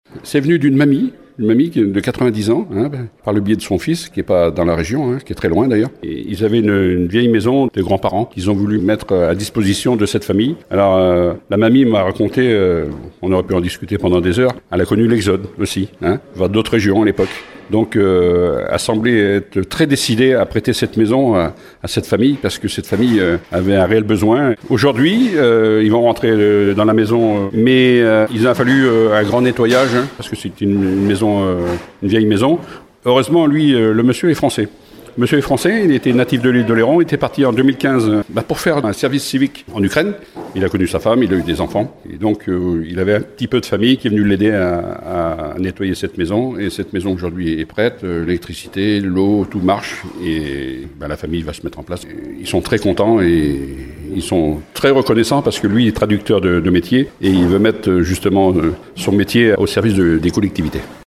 Mais des travaux ont été nécessaires pour l’héberger dans de bonnes conditions, comme le raconte Guy Proteau, maire de Bourcefranc :